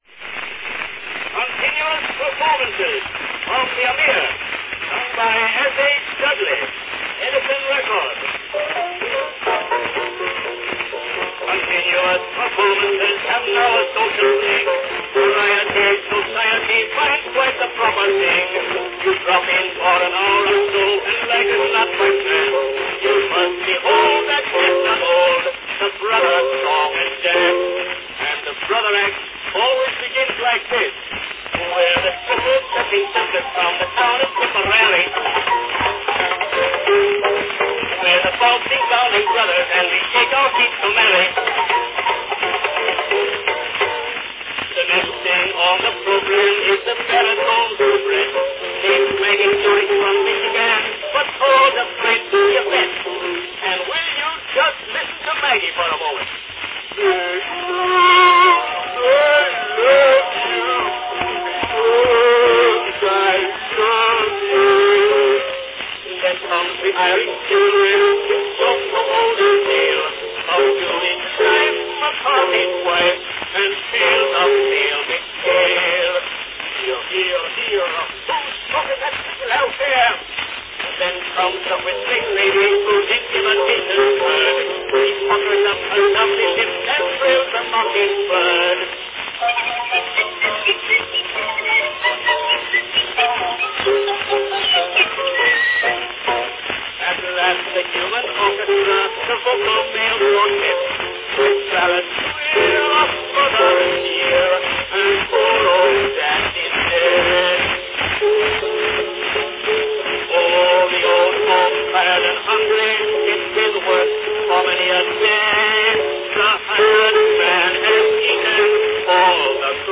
Category Comic song